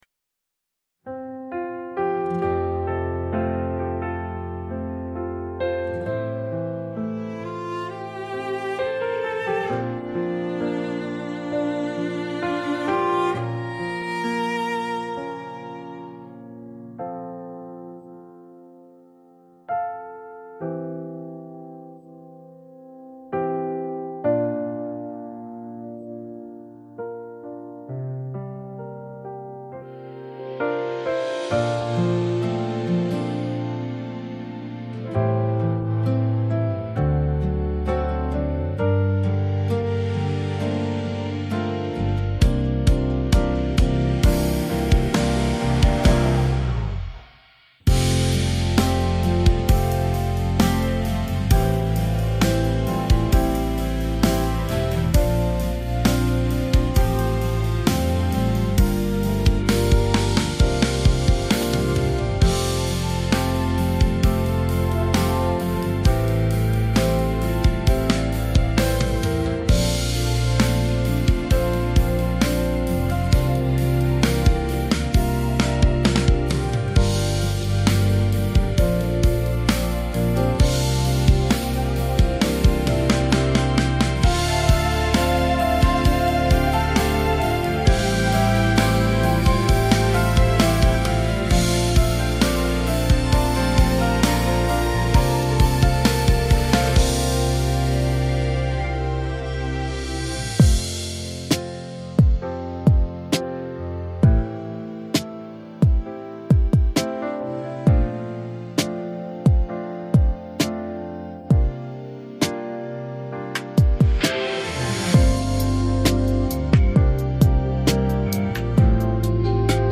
擅長中文、日文、台語、韓語多語創作，曲風融合 EDM、K-POP 與抒情電子。